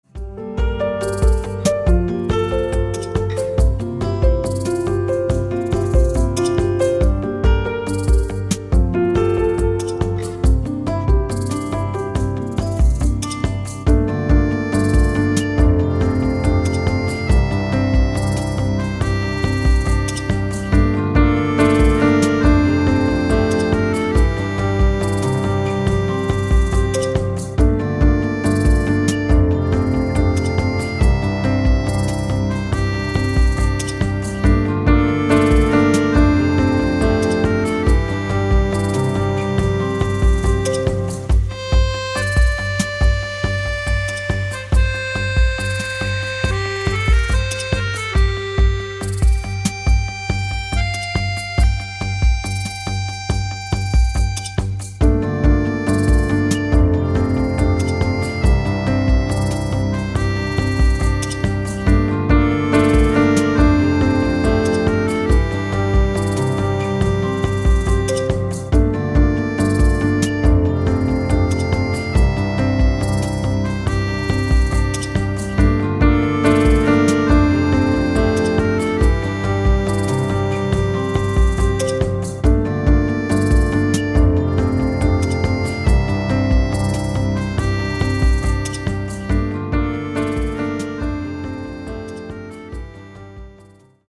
• Качество: 160, Stereo
красивые
спокойные
без слов
Electronica
Downtempo
клавишные
пианино
Ambient